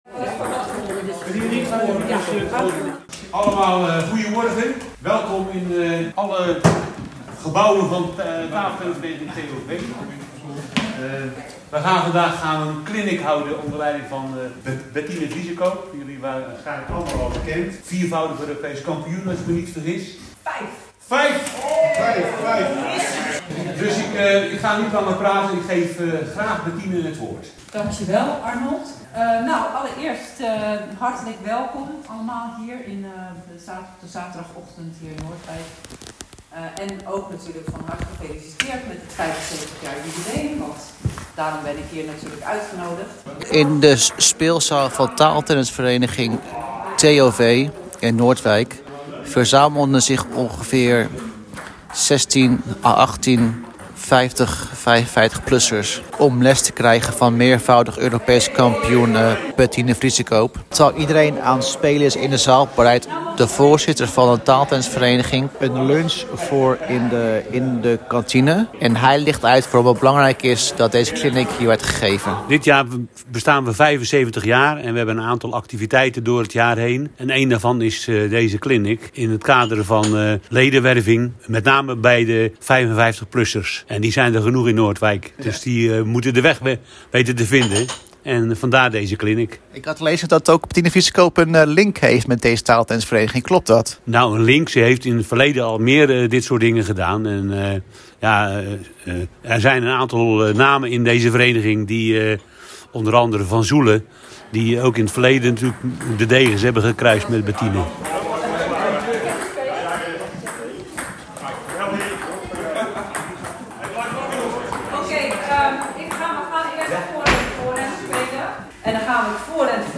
Het zorgt af en toe voor grote hilariteit in de zaal.
audioreportage